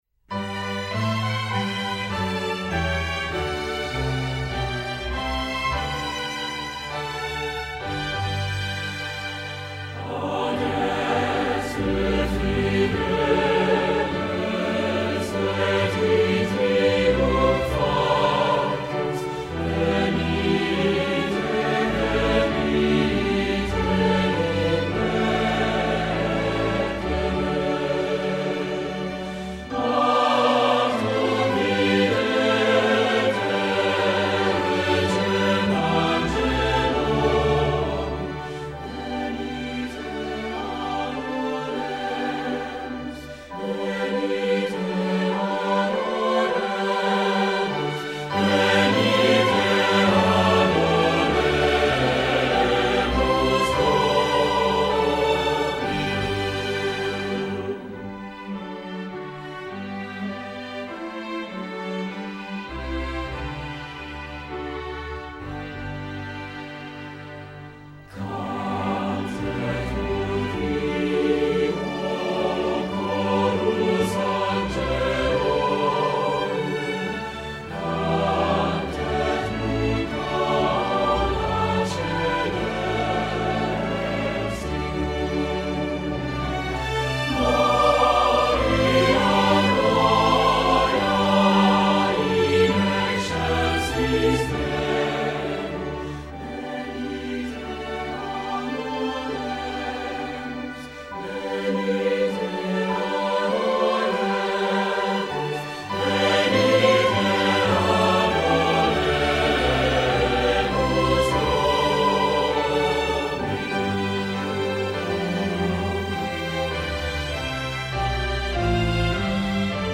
Accompaniment CD Level